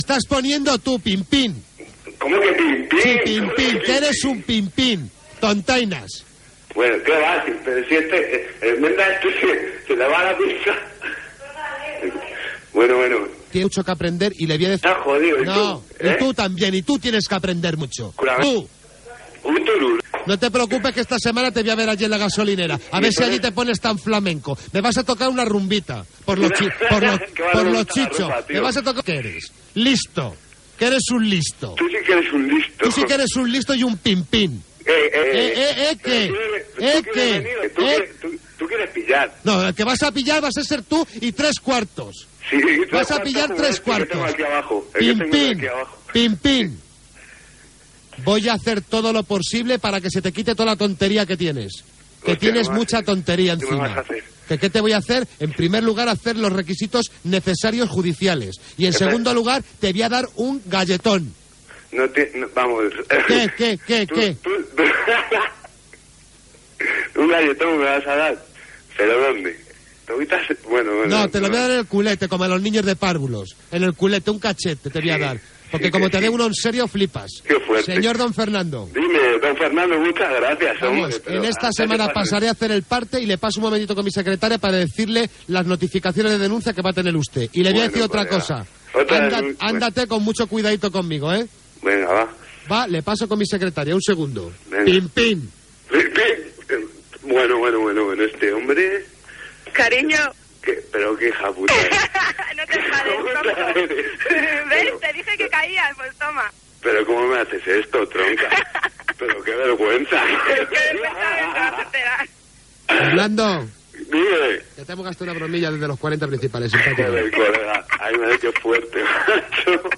Broma telefònica a la parella d'una oïdora, els moviments a la borsa. Indicatius de la cadena i del programa.
FM